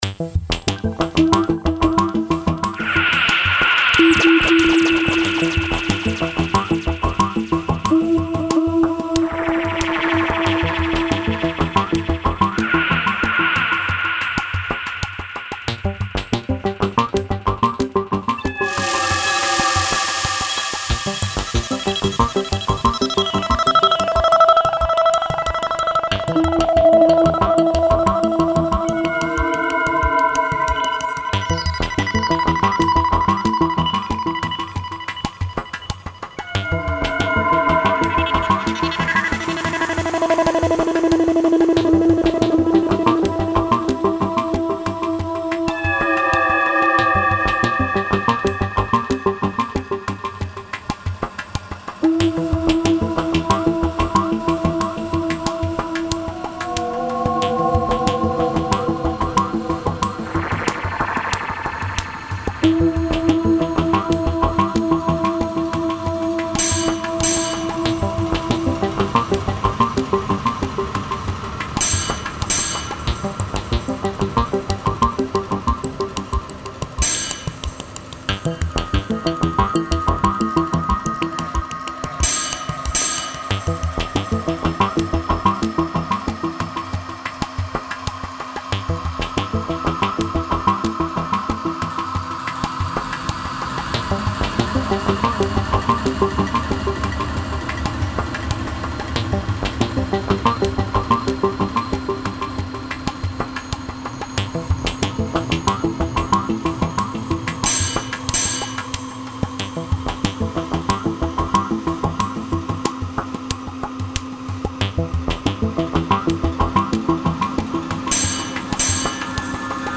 Electronic: